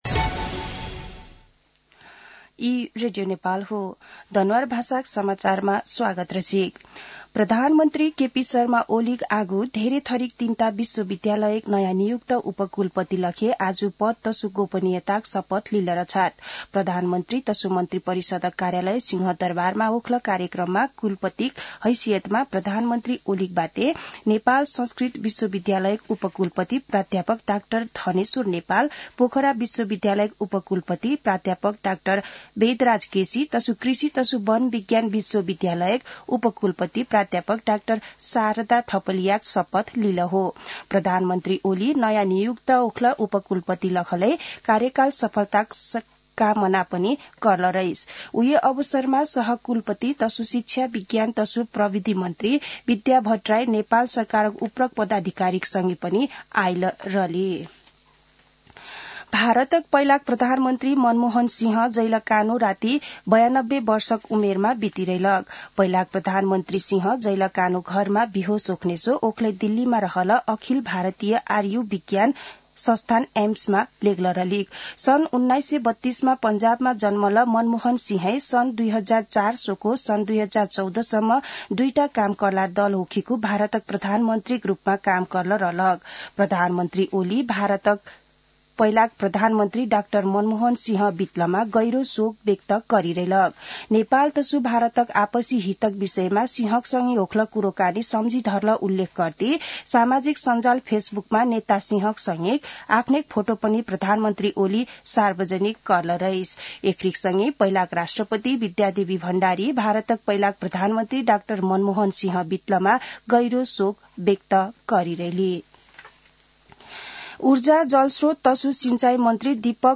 दनुवार भाषामा समाचार : १३ पुष , २०८१
Danuwar-news-2.mp3